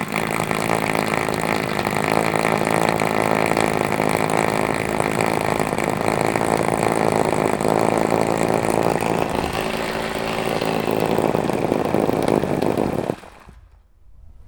• power drill machine close up drilling outdoor.wav
Recorded outdoor in open field with a Tascam DR 40 linear PCM recorder, while drilling from within a concrete basement.
power_drill_machine_close_up_drilling_outdoor_nxr.wav